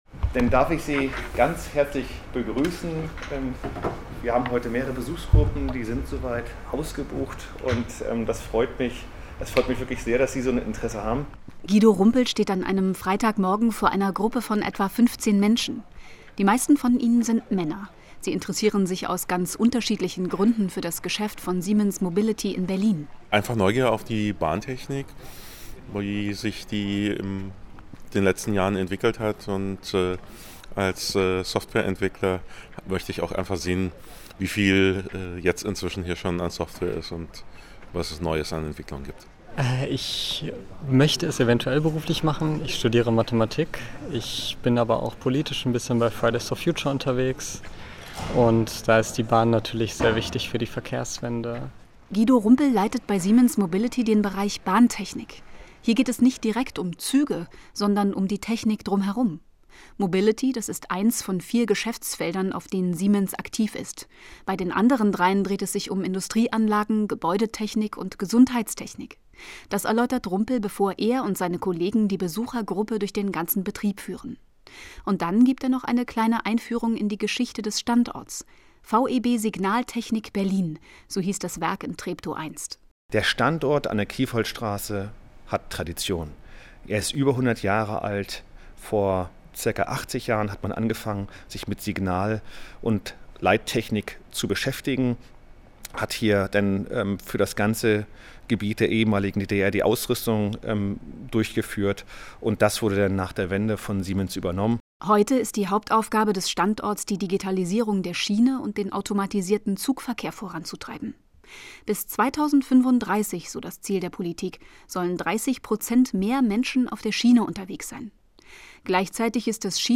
Siemens Mobility will dabei helfen und stellt am Standort Berlin-Treptow das her, was das Bahnnetz digitaler machen soll. Zum Tag der Schiene öffnete das Unternehmen seine Tore für alle Interessierten.
Die Wirtschaftsreportage - Wie das Schienennetz digitaler werden soll